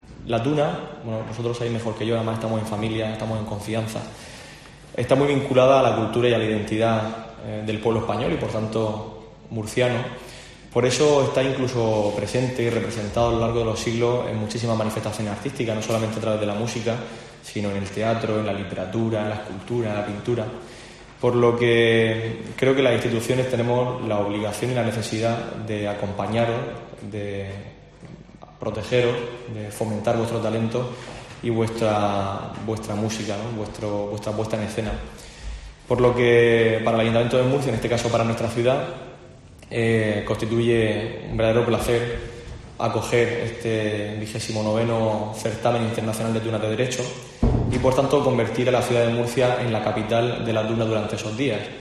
Diego Avilés, concejal de Cultura e Identidad